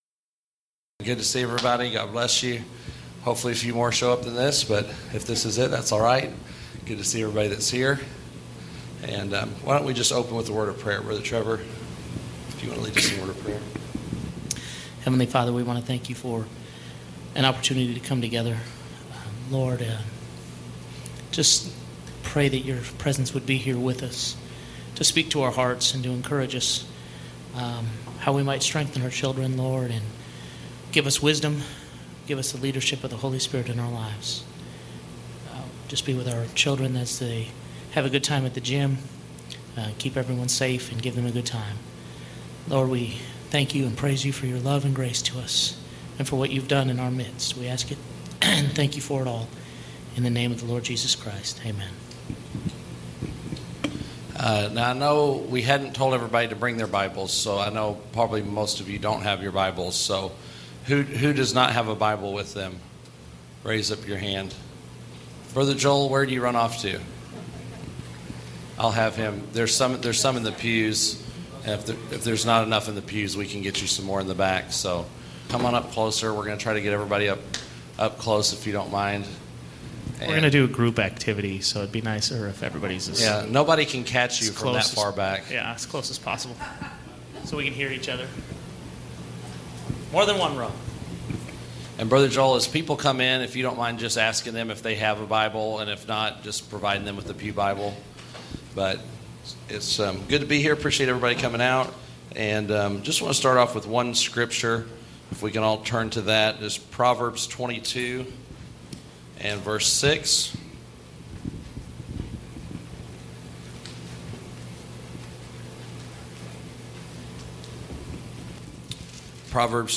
Parent Meeting